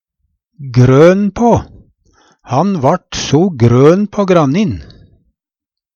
grøn på - Numedalsmål (en-US)